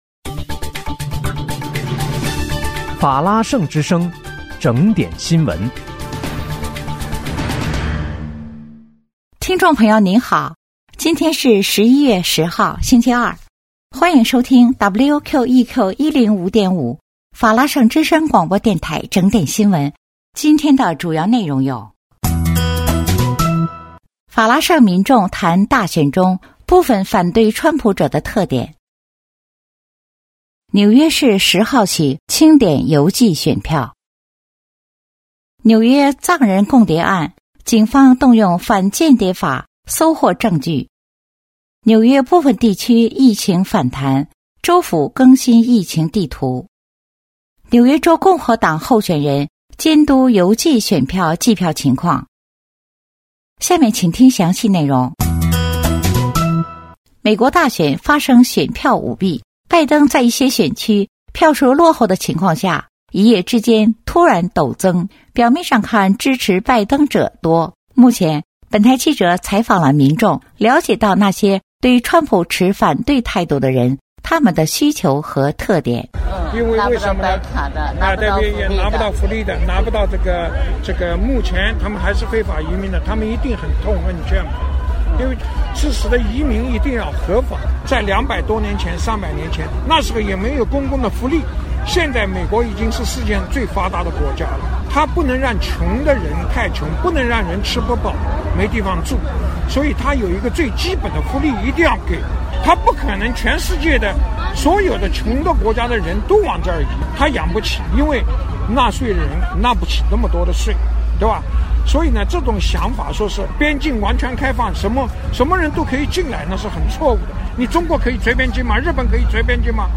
11月10日（星期二）纽约整点新闻